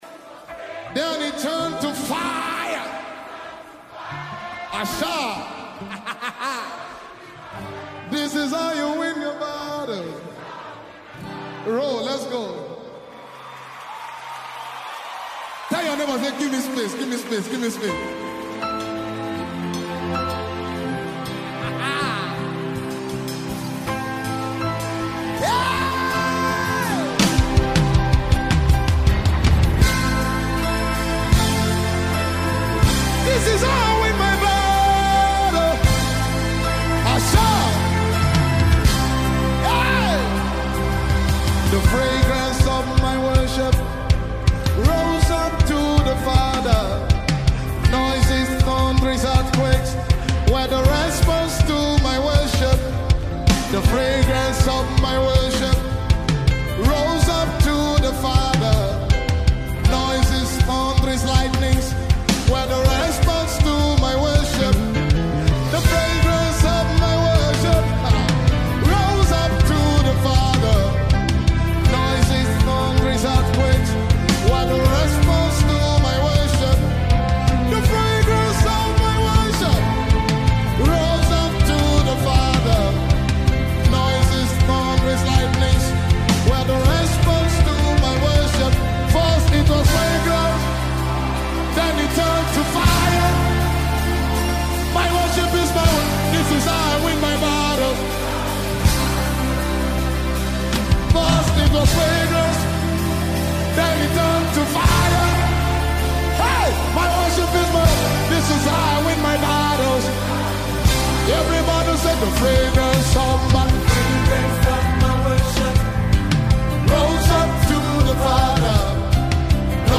Nigerian anointed worship leader